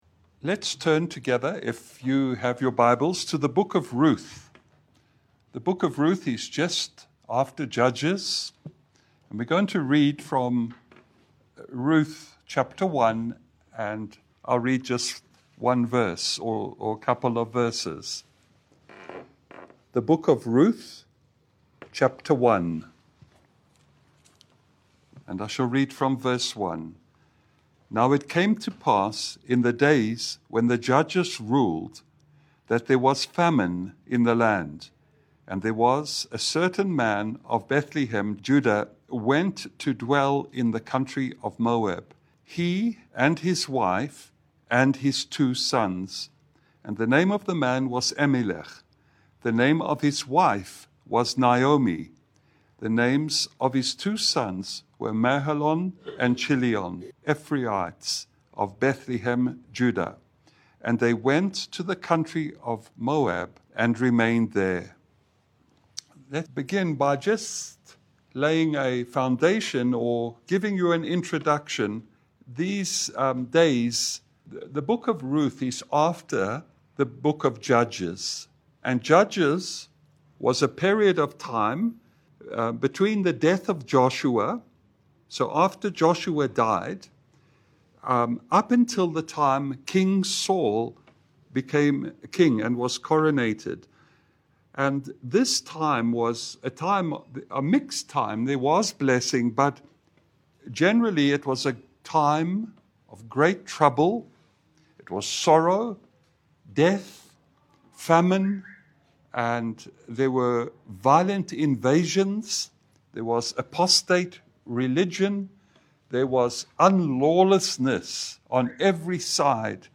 Ruth 1:1 Service Type: Lunch hour Bible Study « Christ the Mediator Understanding Free Will.